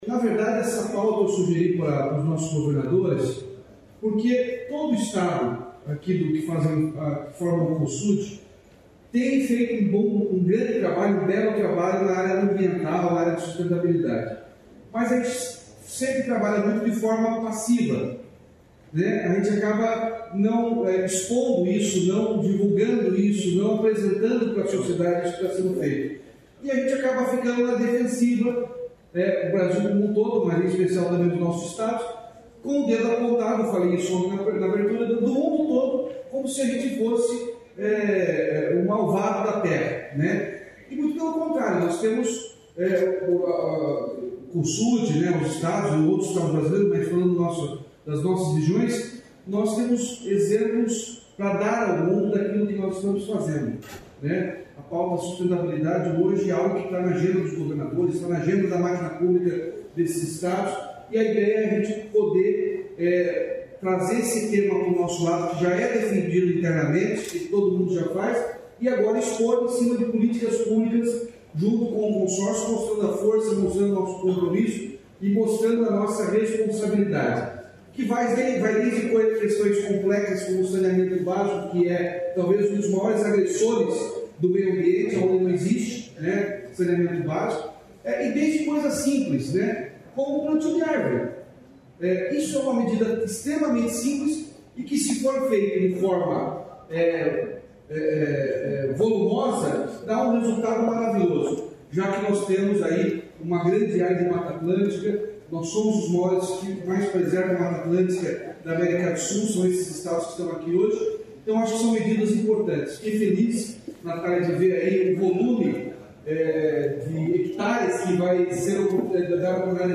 Sonora do governador Ratinho Junior sobre os objetivos ligados a sustentabilidade estabelecidos na reunião do Cosud, em São Paulo